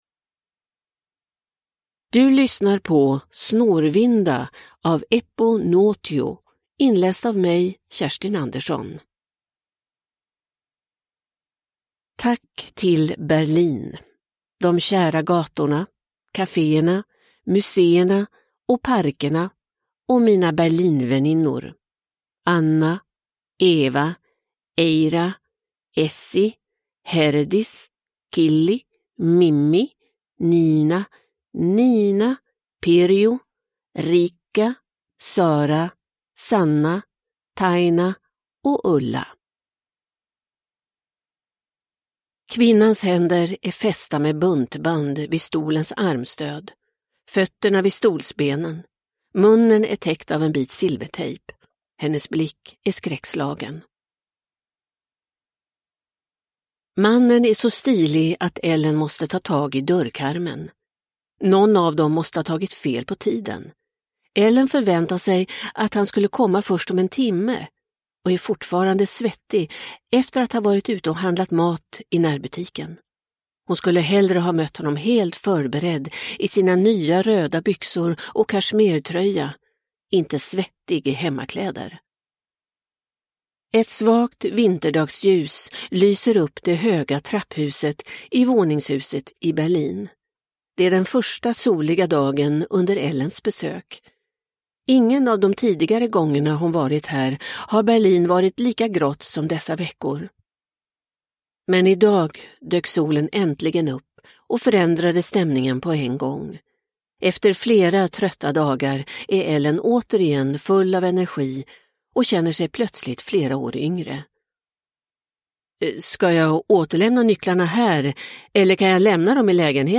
Snårvinda – Ljudbok – Laddas ner